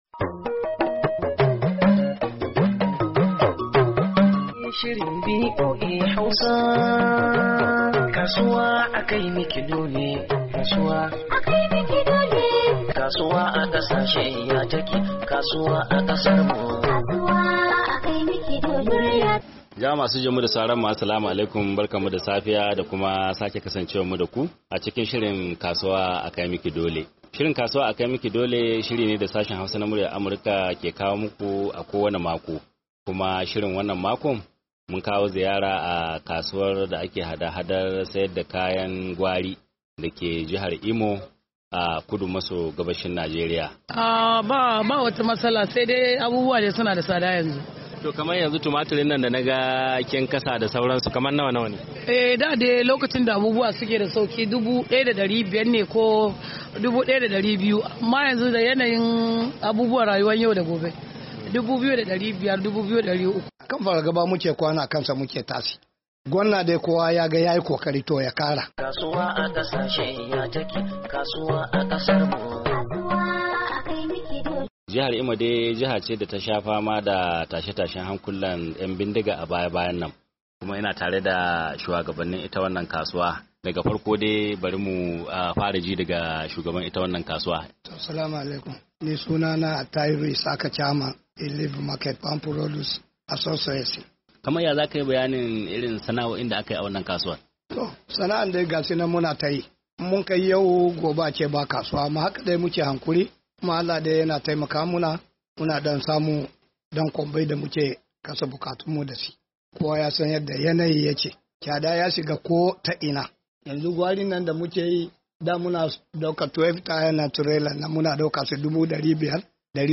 Shirin Kasuwa a kai maki dole na wannan makon ya ziyarci kasuwar kayan gwari, da ke jihar Imo a kudu maso gabashin Najeriya.